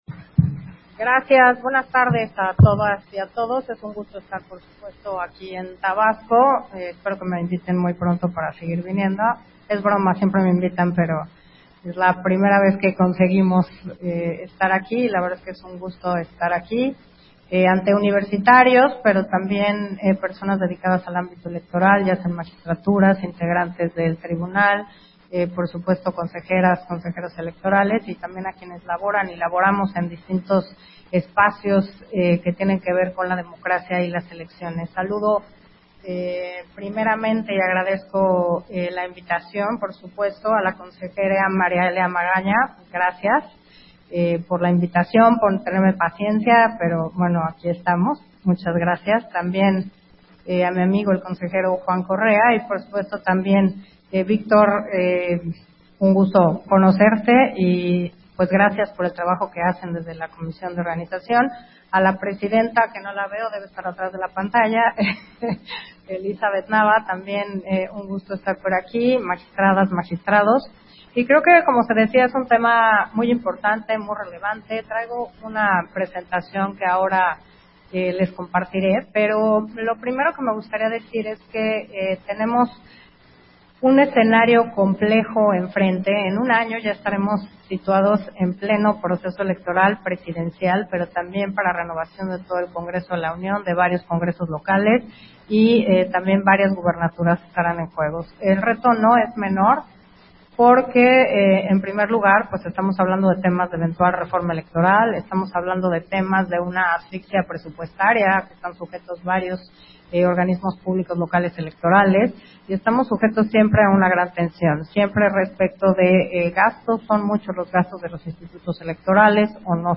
Versión Estenográfica de la Conferencia Magistral, El INE frente a los nuevos desafíos, que ofreció Carla Humphrey, en el marco de la 7º semana: Democracia, Elecciones y construcción de ciudadanía en América Latina